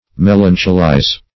Search Result for " melancholize" : The Collaborative International Dictionary of English v.0.48: Melancholize \Mel"an*cho*lize\, v. i. To become gloomy or dejected in mind.
melancholize.mp3